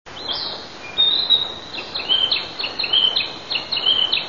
fnarcissina1.wav